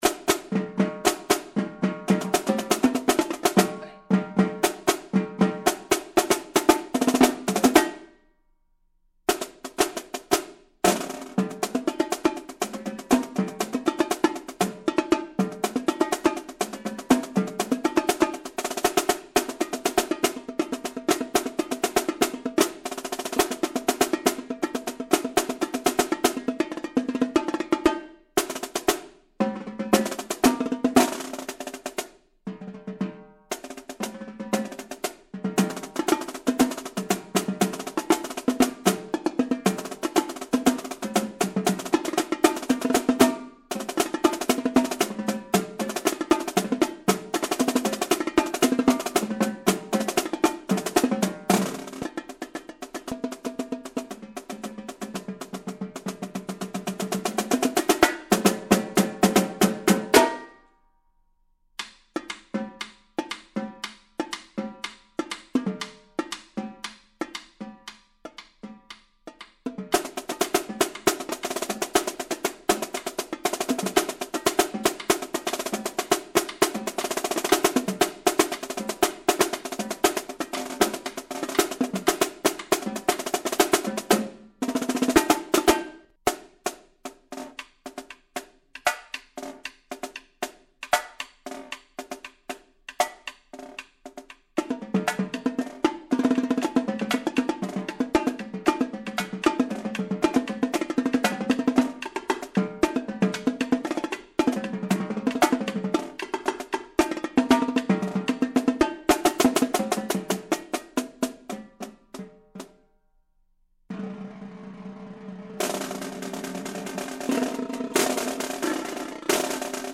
Voicing: Percussion Duet